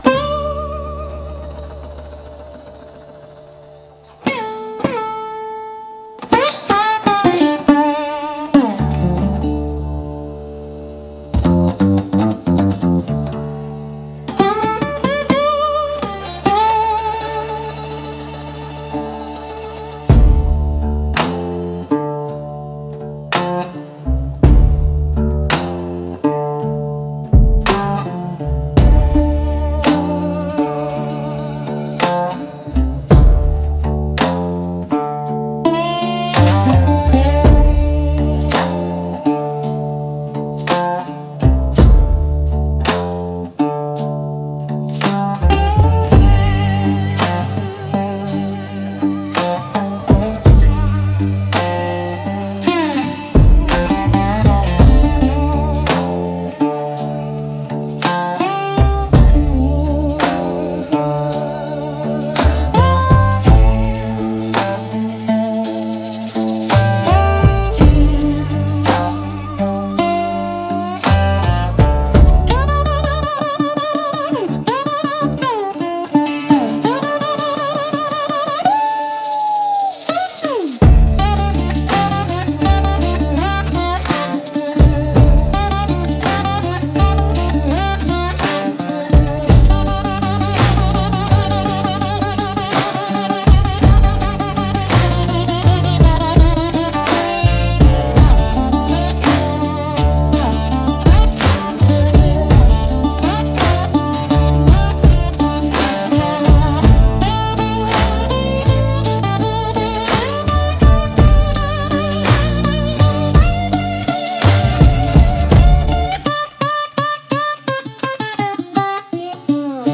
Instrumental - Judgement in Jubilee 3.06